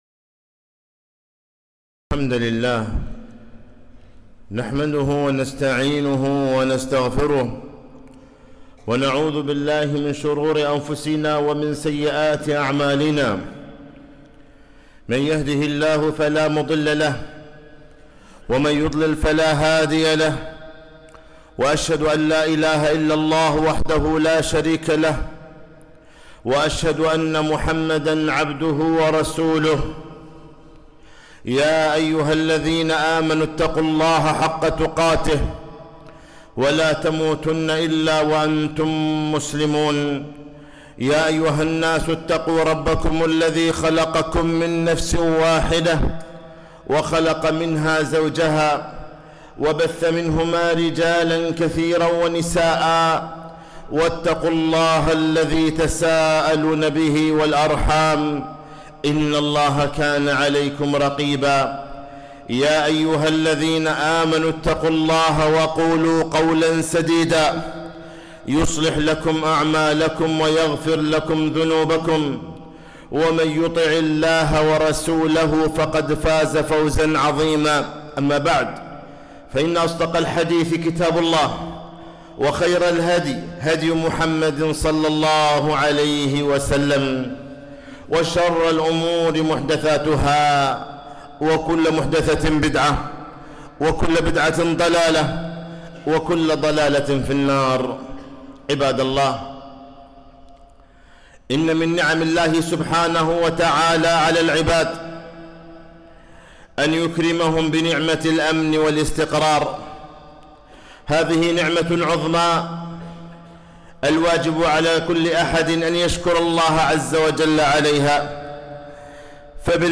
خطبة - طاعة ولي الأمر من دين الله